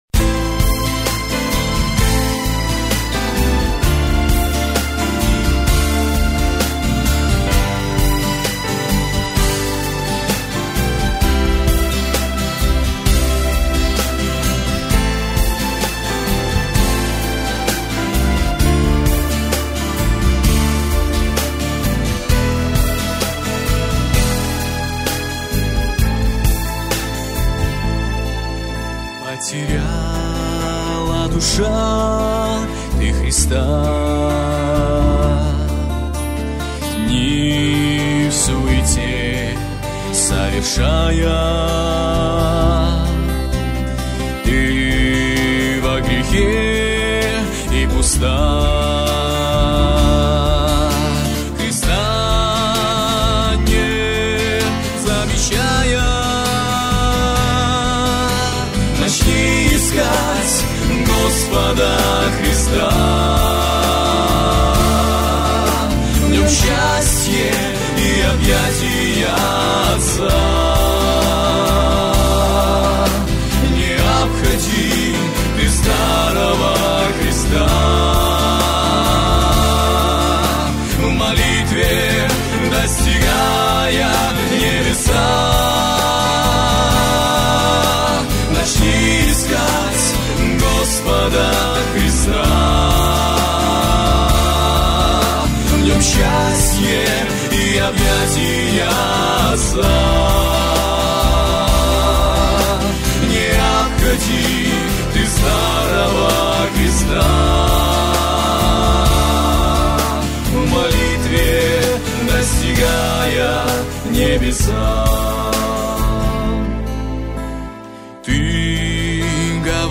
песня
4 просмотра 0 прослушиваний 0 скачиваний BPM: 130 4/4